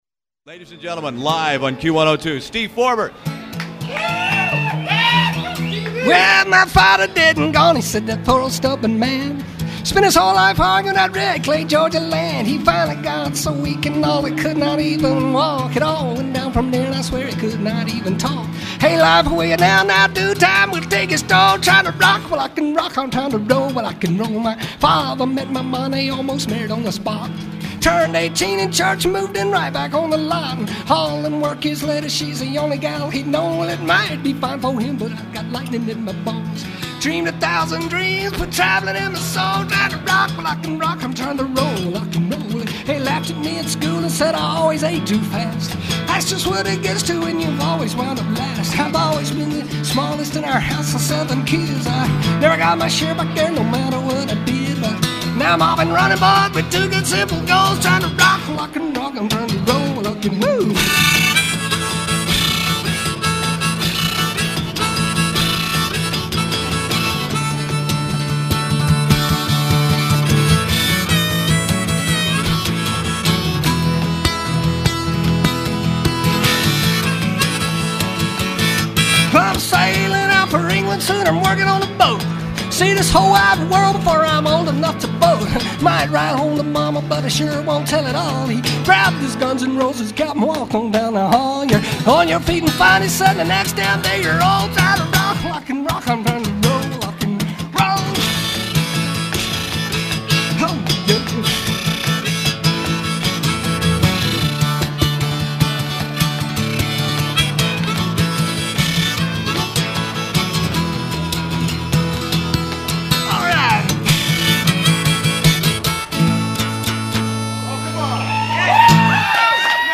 You can dock me points for the minimalist recorded sound